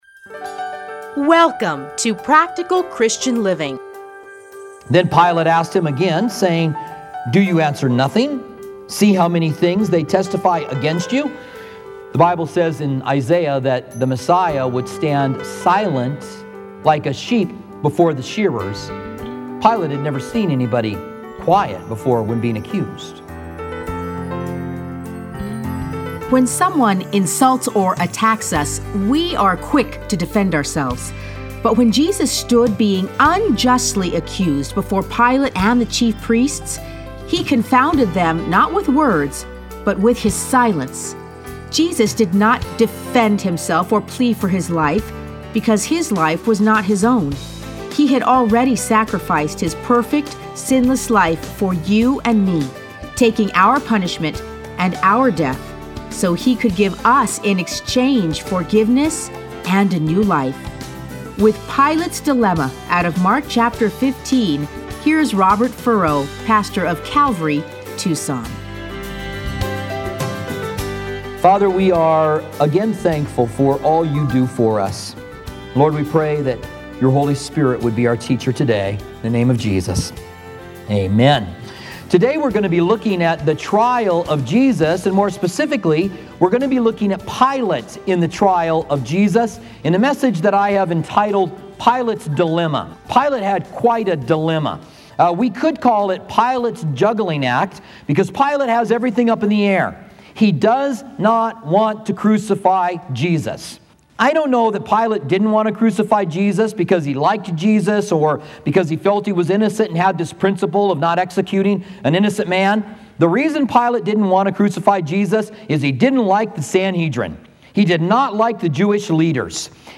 Listen to a teaching from Mark 15:1-20.